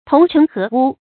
同塵合污 注音： ㄊㄨㄙˊ ㄔㄣˊ ㄏㄜˊ ㄨ 讀音讀法： 意思解釋： 謂行為同于流俗之人，合于污濁之世。